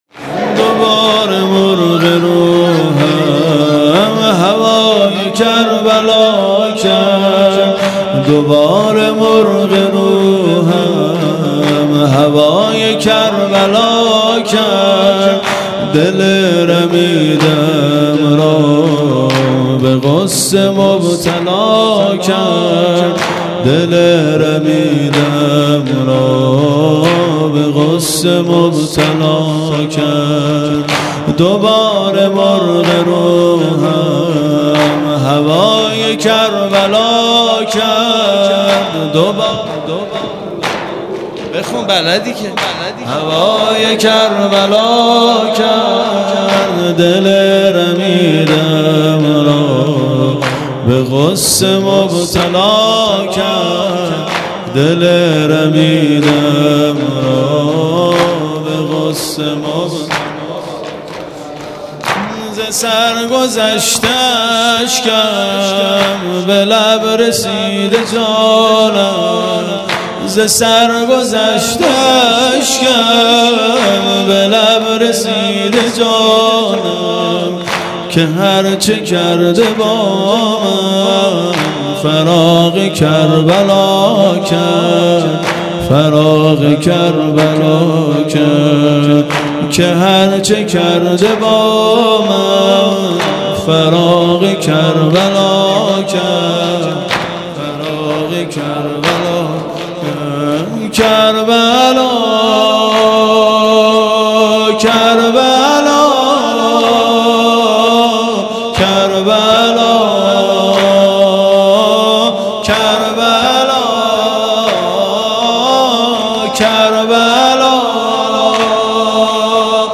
0 0 شور